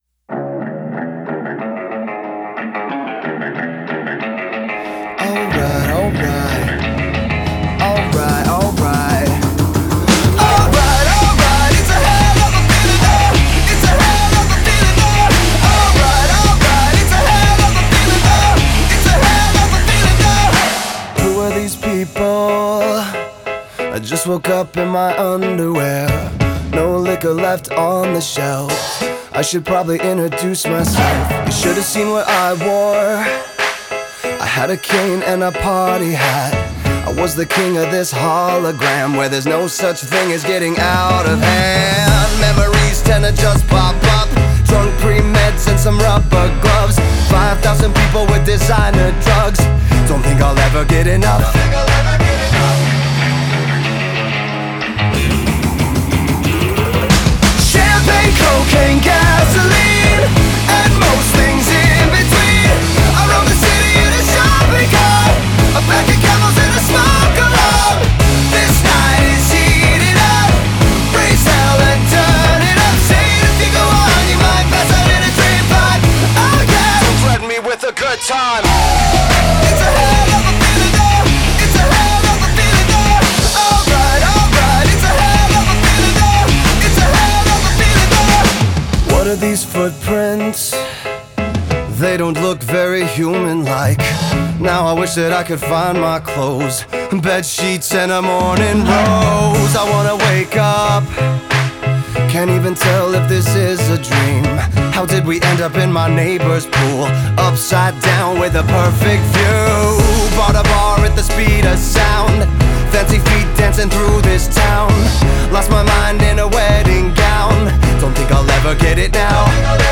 Genre: Alternative Rock, Pop Rock